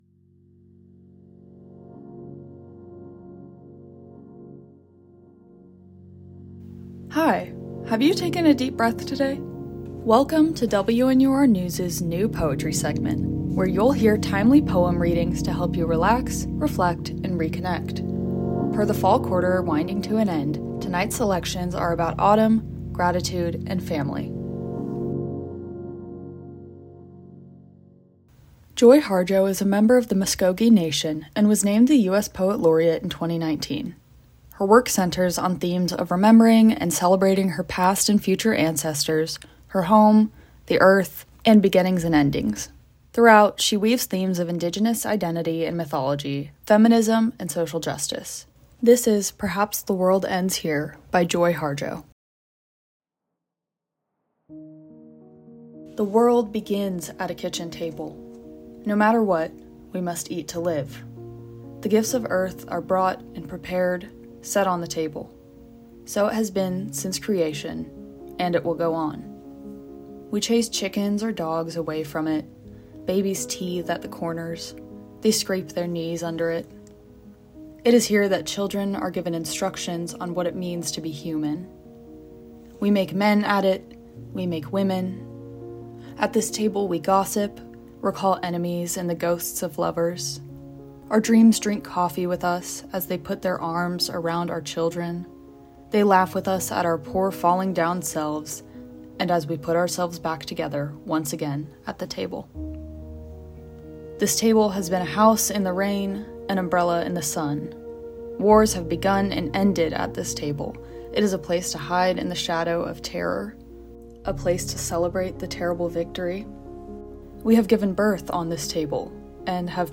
Not to mention, we wouldn’t miss an opportunity to turn written word into spoken. Tune in for the very first episode of our new poetry special segment.
soft synth music by Soul_Serenity_Ambience | Pixabay